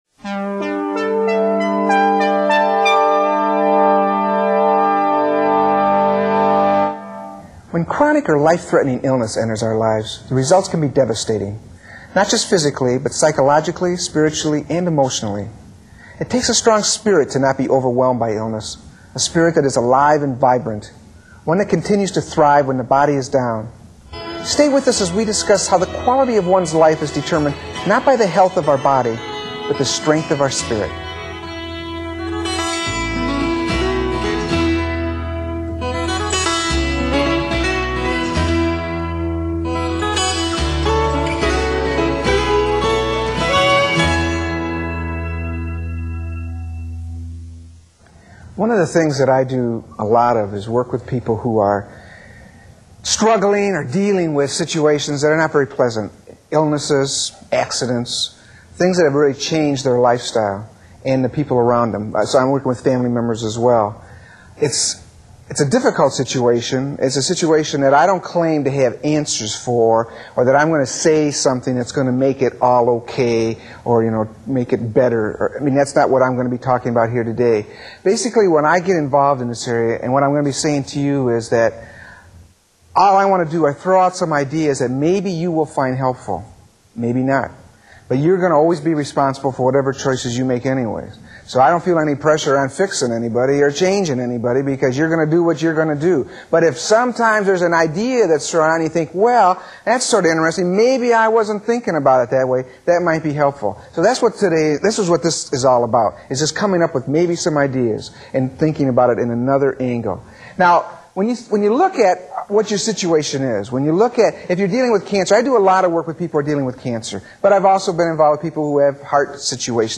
Surviving With Spirit – Audio (Public Televison Lecture) (Digital)
A talk from my 6 part series on Public TV called “Who’s In Charge?” This one focuses on keeping your spirit alive when you are dealing with a serious illness.